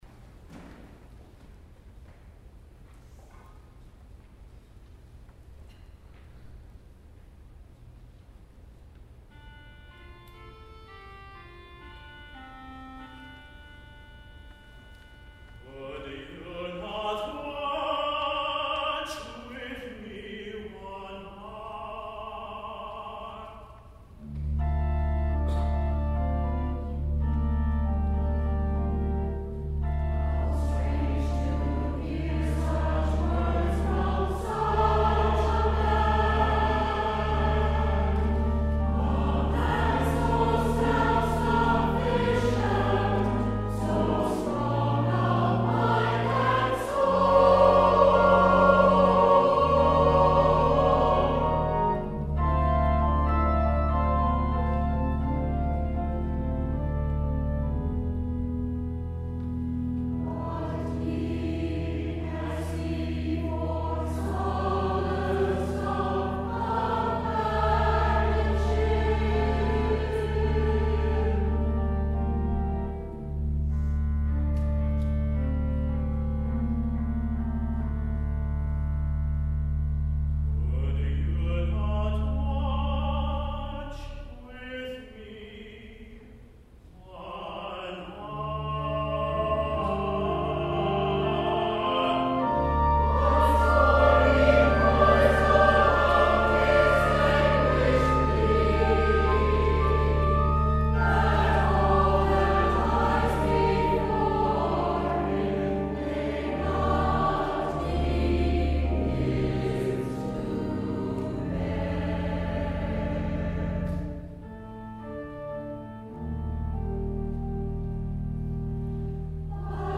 The St. William choir presented a Tenebrae Service on Palm Sunday 2015.